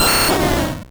Cri d'Herbizarre dans Pokémon Rouge et Bleu.